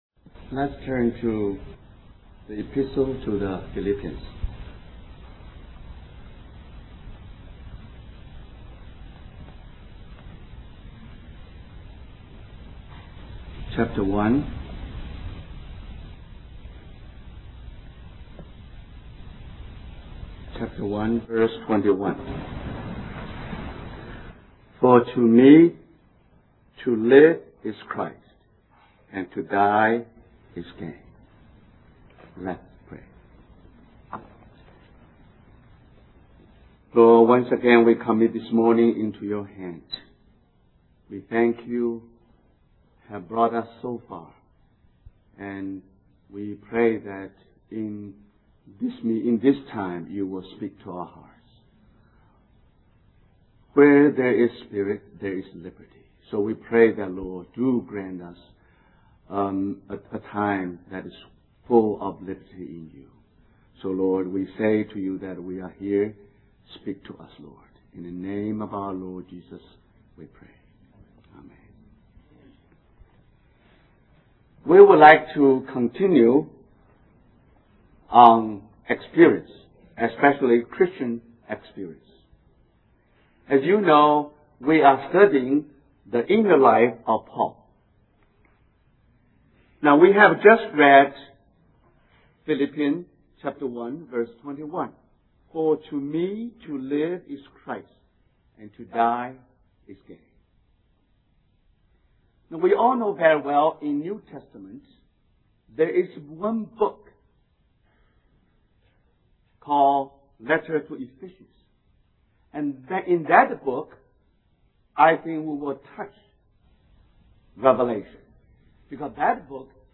We apologize for the poor quality audio